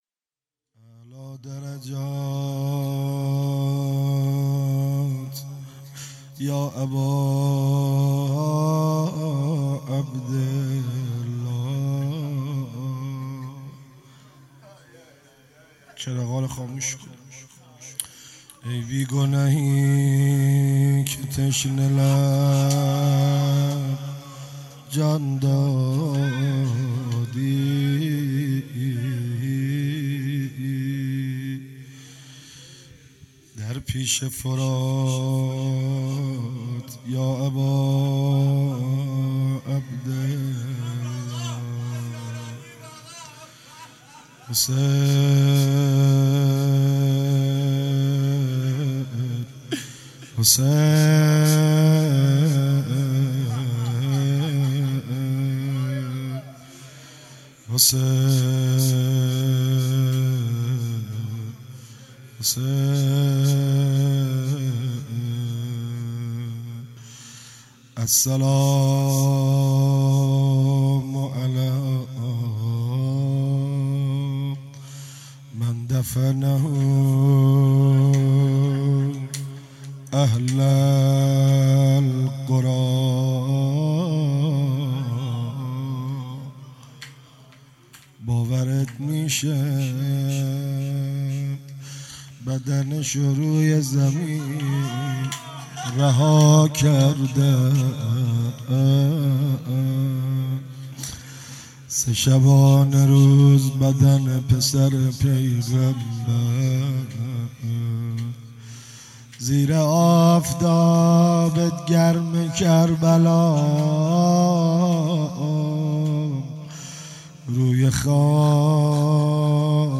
هفتگی 20اسفند_روضه پایانی_اعلا درجات یا اباعبدالله
مداحی